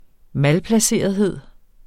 Udtale [ ˈmalplaˌseˀʌðˌheðˀ ]